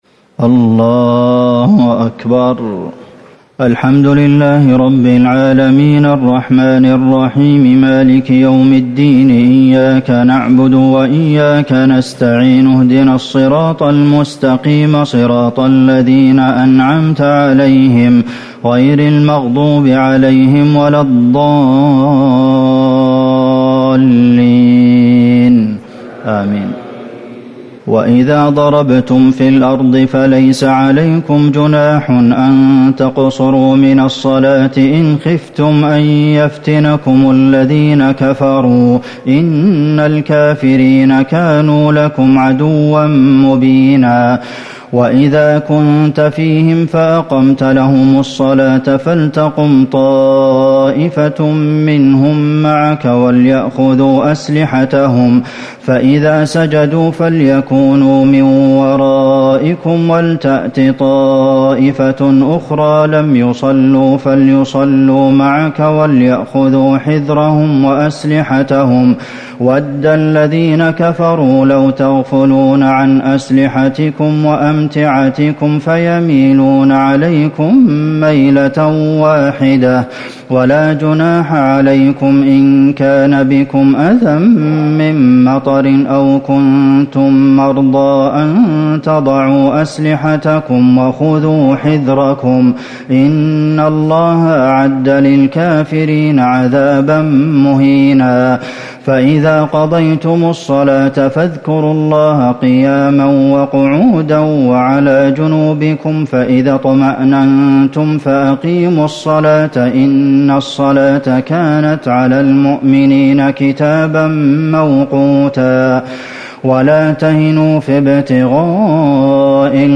تهجد ٢٥ رمضان ١٤٤٠ من سورة النساء ١٠١ - ١٤٨ > تراويح الحرم النبوي عام 1440 🕌 > التراويح - تلاوات الحرمين